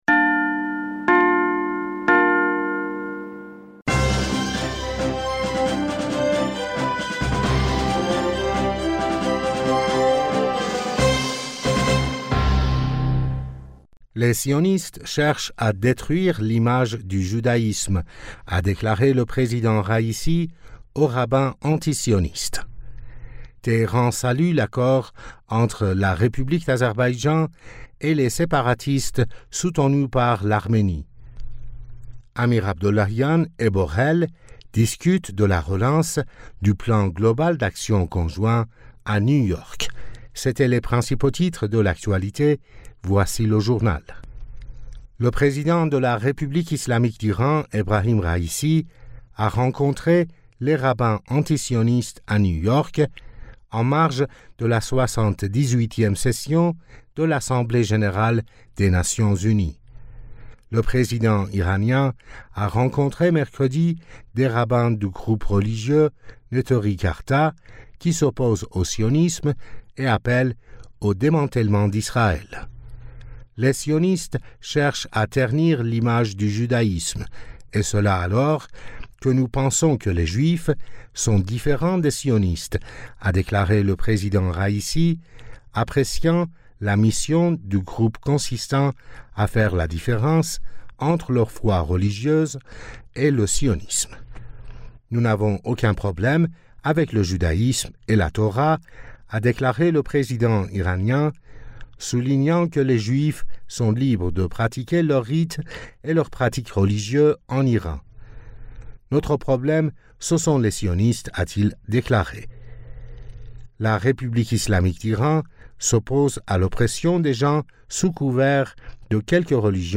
Bulletin d'information du 21 Septembre 2023